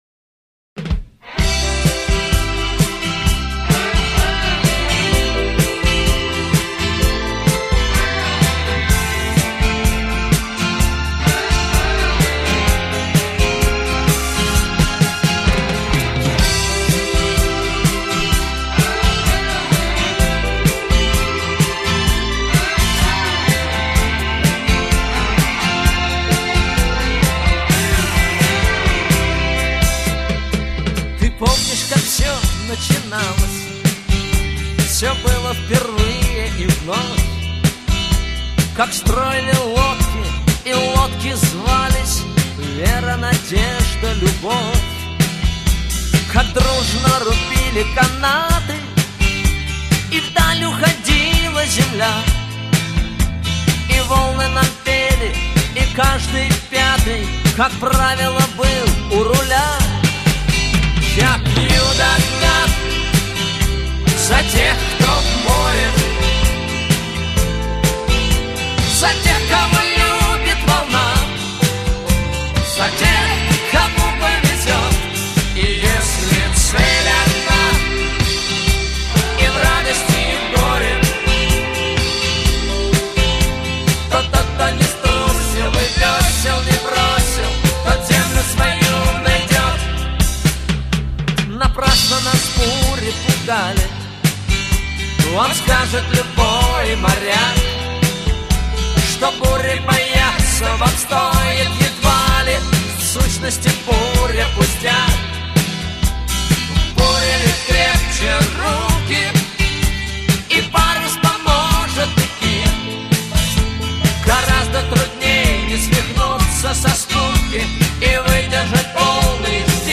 Такт 4/4 (Темп 140)